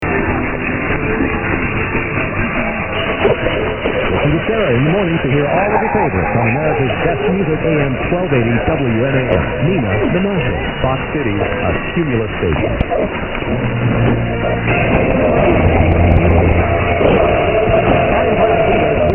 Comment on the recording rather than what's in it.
121029_0700_970_rebelde_mix_wzan.mp3